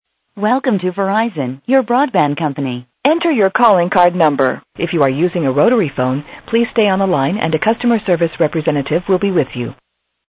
The following are examples of other local or long distance telephone company operator service platforms sounds and recordings.